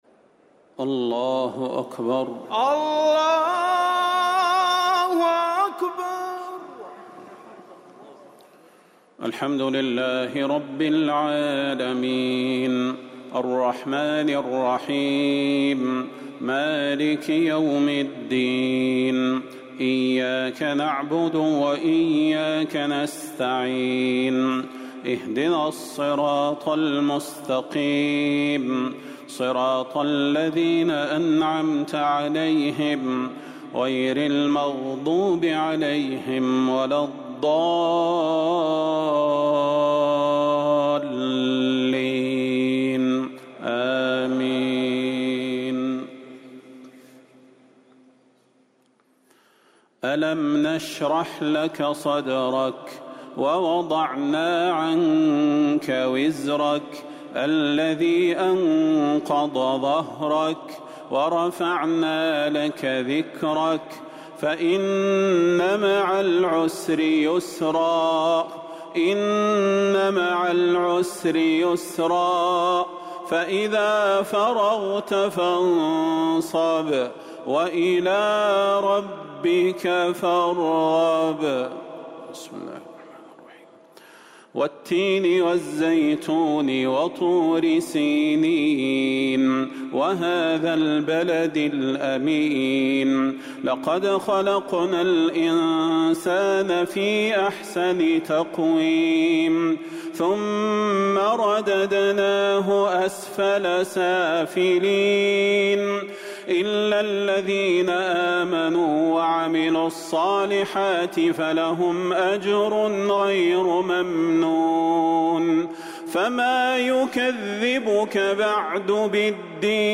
تراويح ليلة 29 رمضان 1444هـ من سورة الشرح إلى سورة الناس | taraweeh 29 st niqht Ramadan 1444H from Surah Ash-Sharh to An-Naas > تراويح الحرم النبوي عام 1444 🕌 > التراويح - تلاوات الحرمين